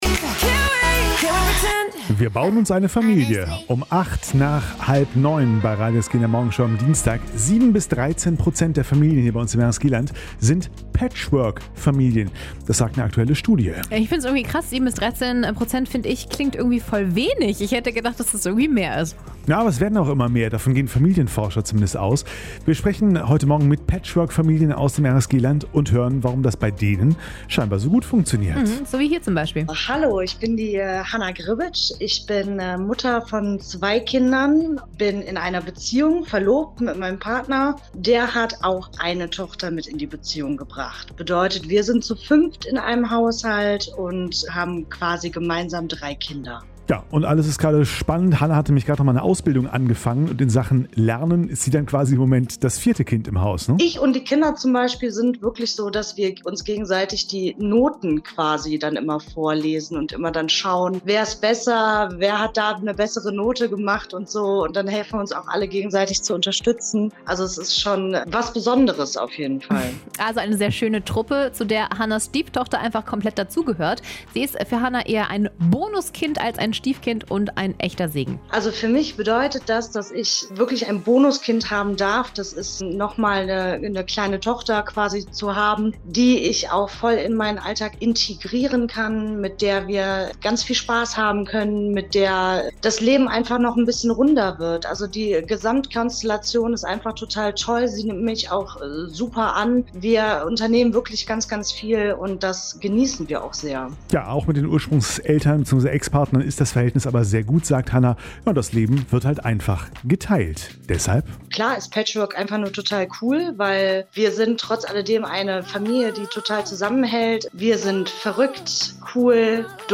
Mehrere RSG-Hörer haben uns in der Morgenshow von ihrem Familiengeschichten erzählt.